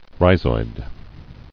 [rhi·zoid]